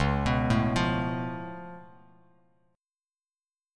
C#+7 chord